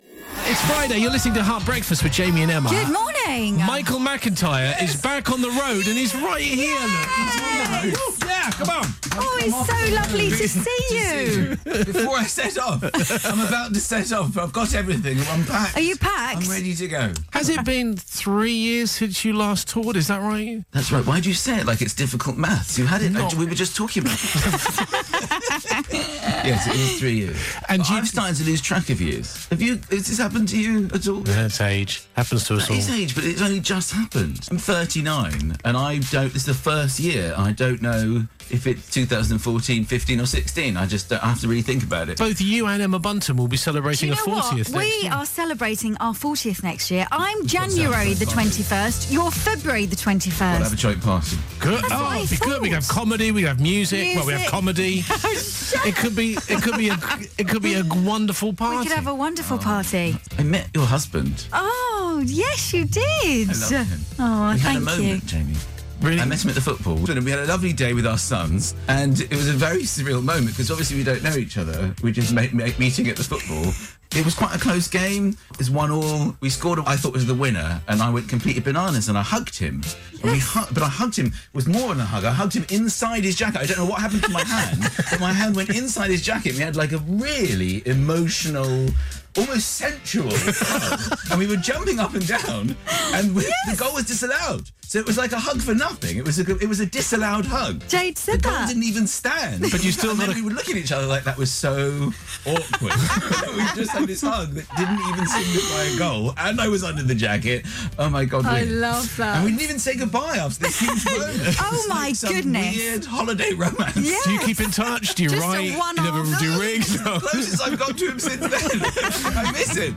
Michael McIntyre came in for a chat on London's favourite breakfast show talking school reports, a sensual hug, and his new tour.